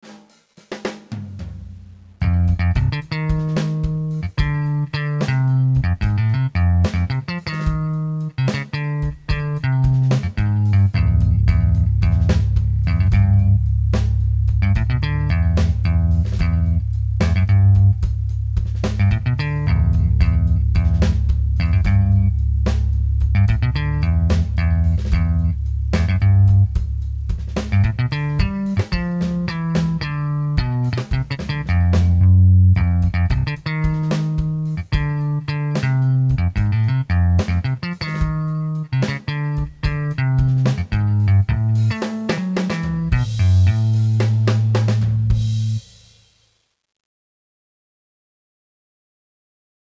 Dabei handelt es sich um einen fünfsaitigen Bass, der sich vor allen Dingen für Rock und Metal eigenen soll.
Für die Klangbeispiele habe ich Bassläufe mit unterschiedlichen Presets eingespielt.